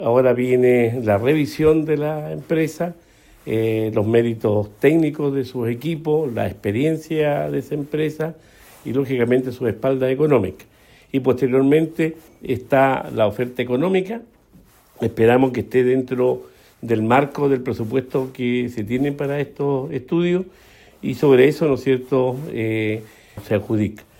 Por su parte, el gobernador regional, Luis Cuvertino, detalló los pasos a seguir tras el cierre de la licitación, donde se analizarán los antecedentes de la única empresa oferente.